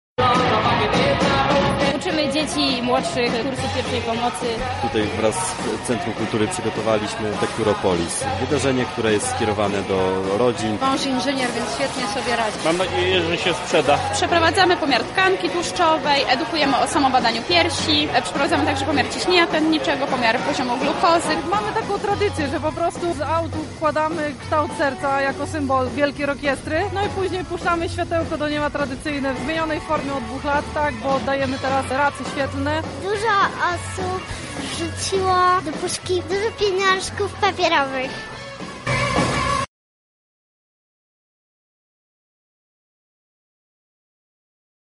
Wieczorem na placu Zamkowym fani aut z grupy VAG wysłali tradycyjne światełko do nieba. Na miejscu była nasza reporterka.
Relacja